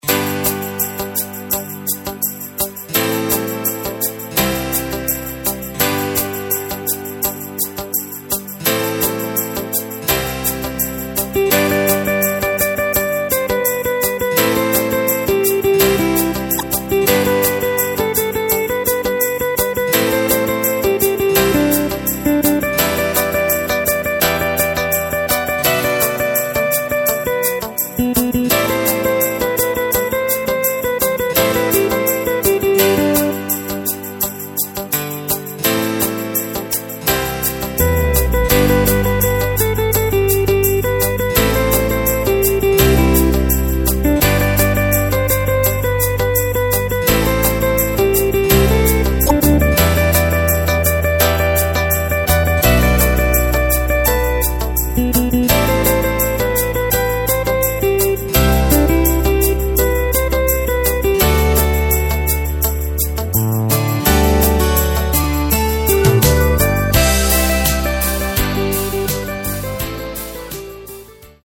Takt:          2/4
Tempo:         84.00
Tonart:            G
Austropop aus dem Jahr 1989!
Playback mp3 Demo